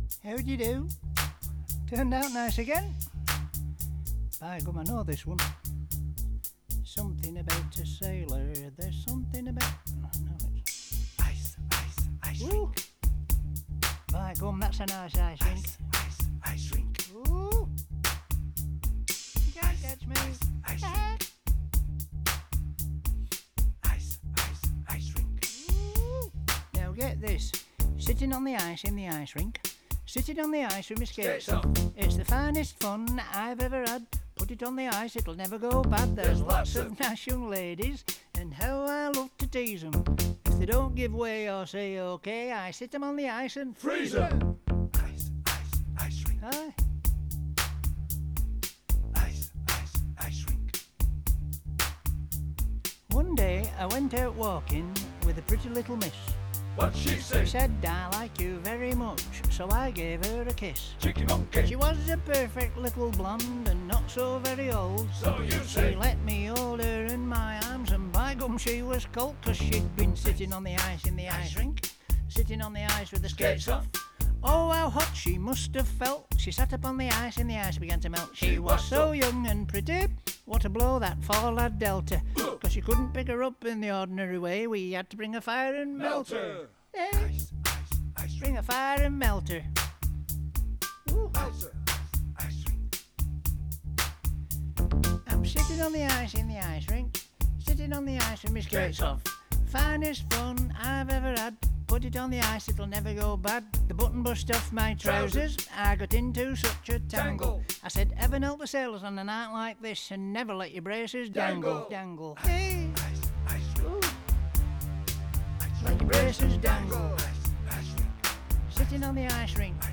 rap version